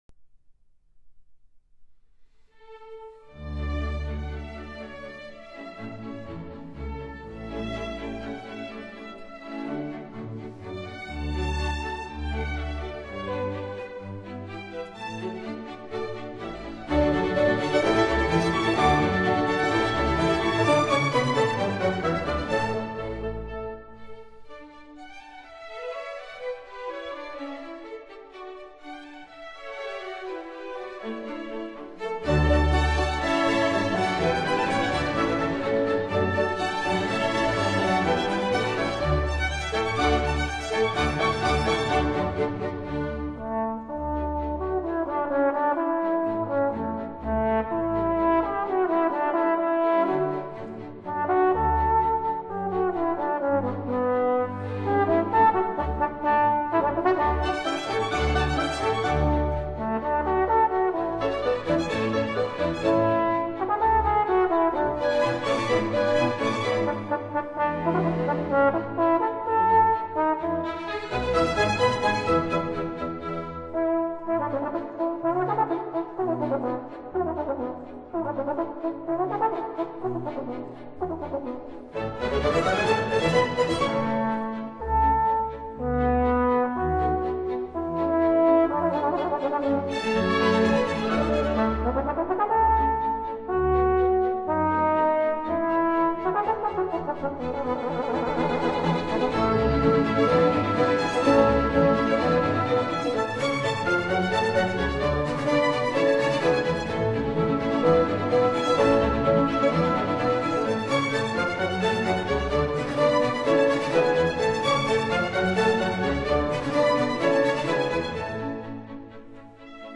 (長號版)